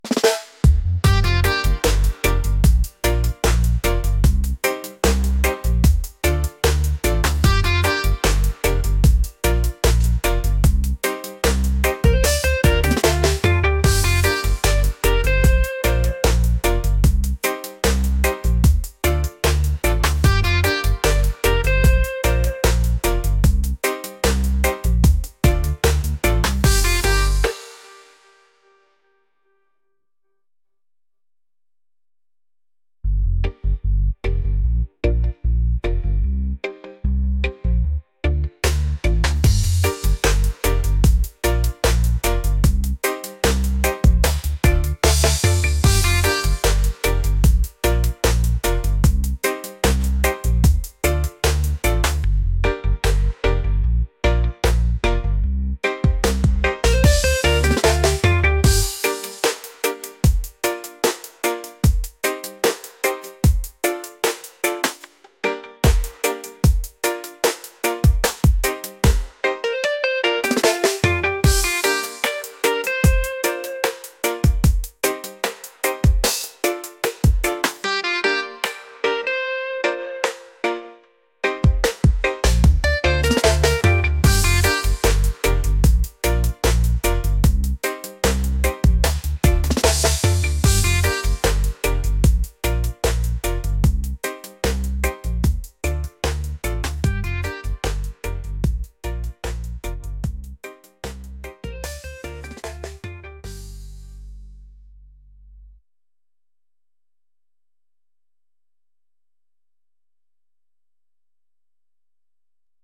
reggae | island | laid-back